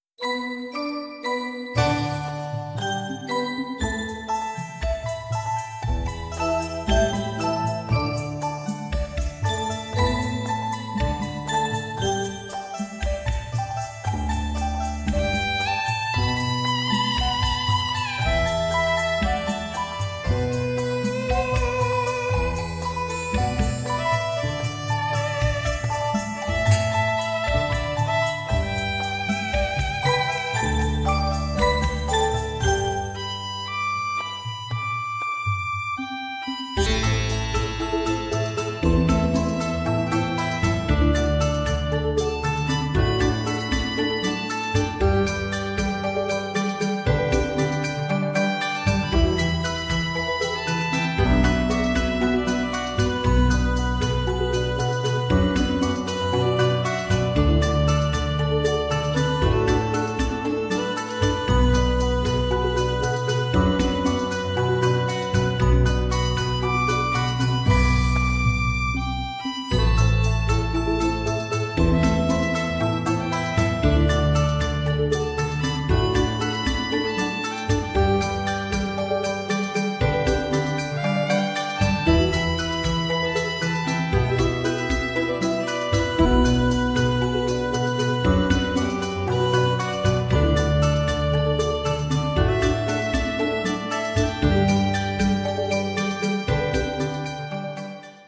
Bel Stasiun Semarang Poncol
di setiap kedatangan Kereta.